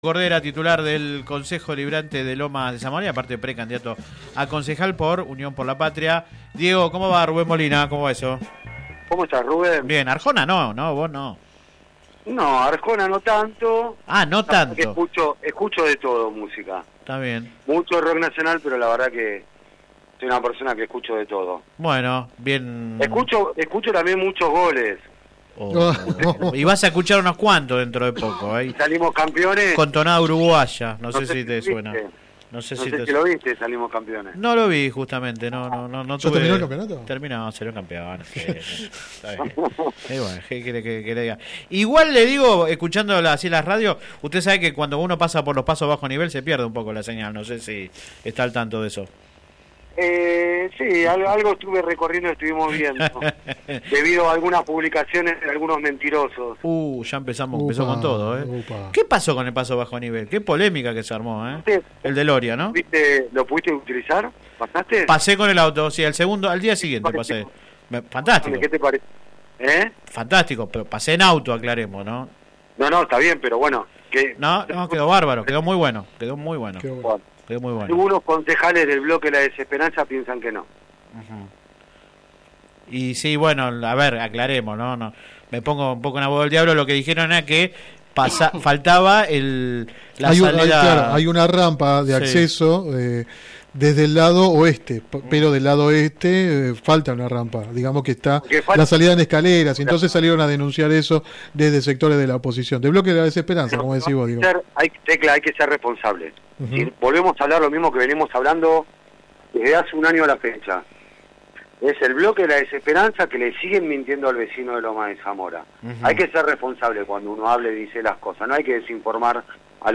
El edil oficialista habló en el programa radial Sin Retorno (lunes a viernes de 10 a 13 por GPS El Camino FM 90 .7 y AM 1260).
Click acá entrevista radial